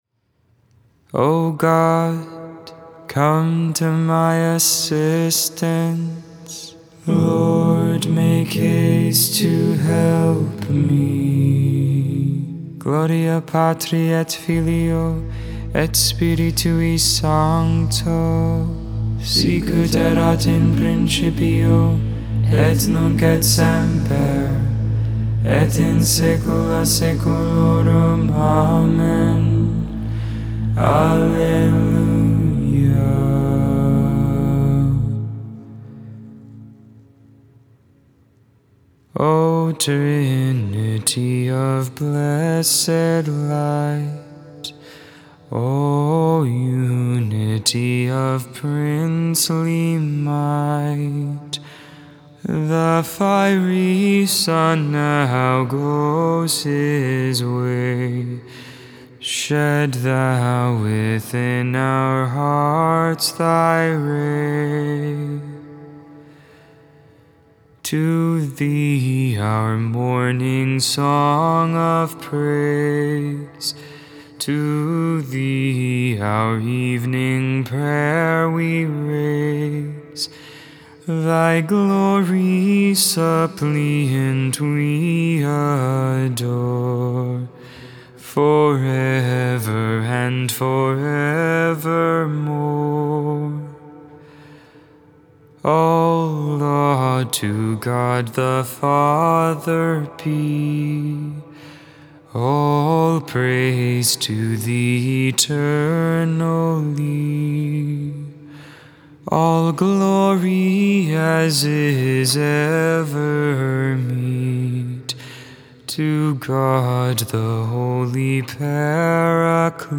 1.23.22 Vespers, Sunday Evening Prayer
Vespers II, Sunday Evening Prayer on the 3rd Sunday in Ordinary Time, January 23rd, 2022.